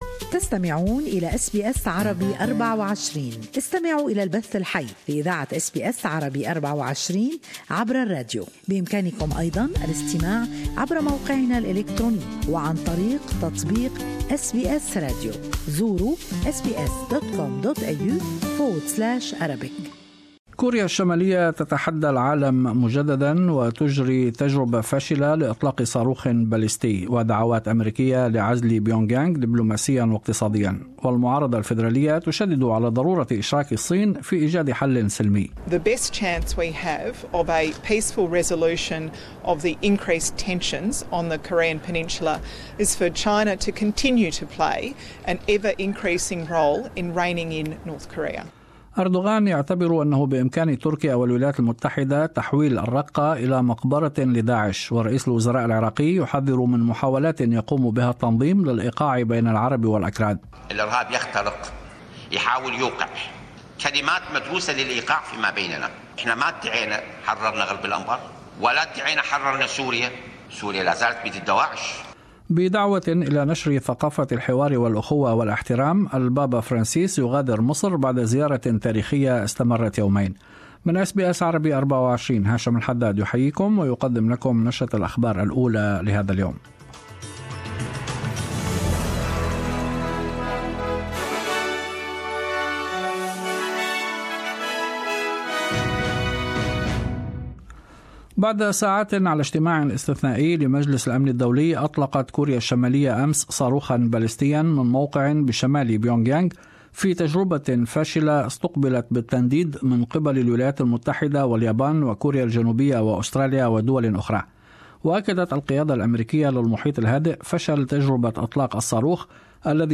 Latest Australian and world news in the morning news bulletin.